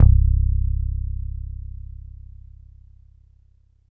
14 BASS C2.wav